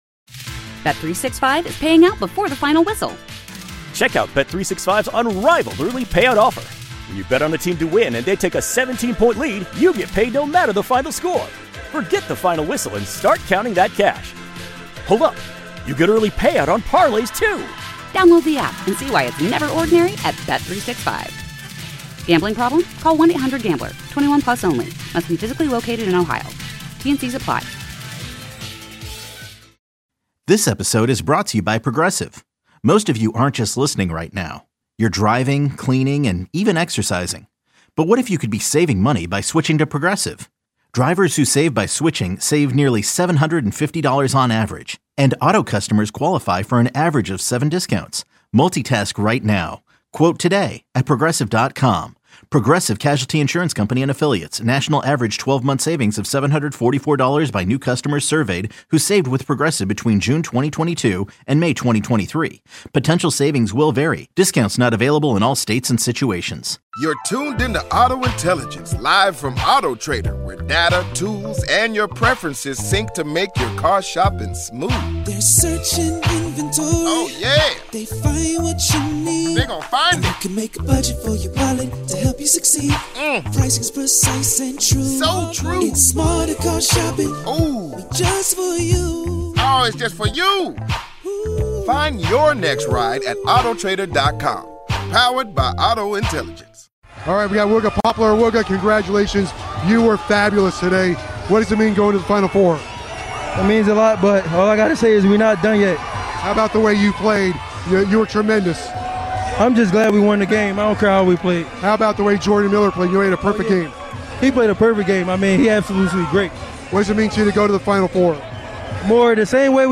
interviews
on the court after the Canes Biig Win against Texas to advance to the Final Four!!!